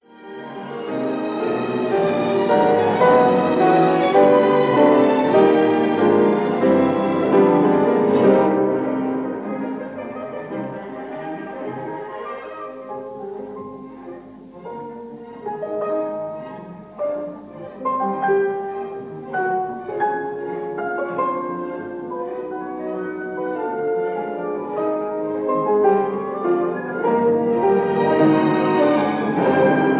Recorded live at a 1955 season
given in the Royal Albert Hall, London